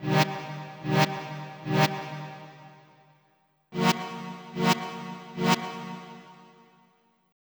CLF Rev Stab Riff E-Eb-Gb-Bb.wav